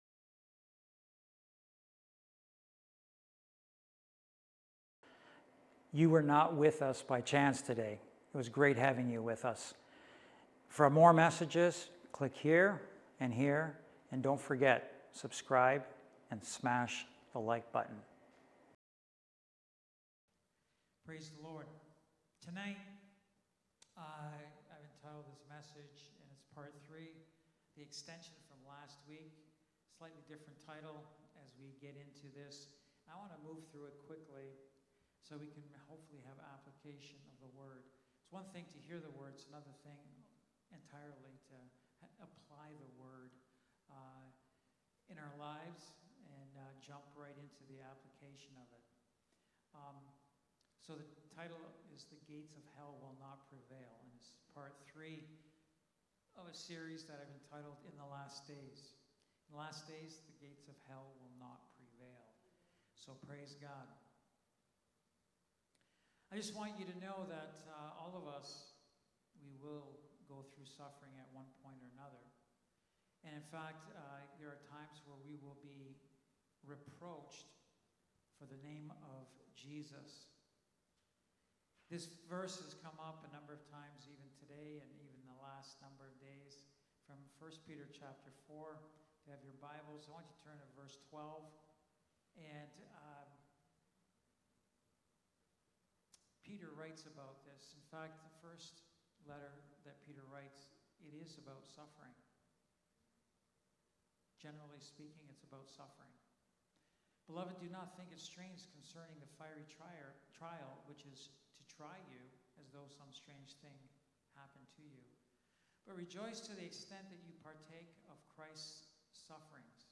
Wednesday Night Bible Study
Lighthouse Niagara Sermons